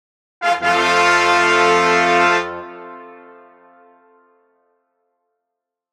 tada.aiff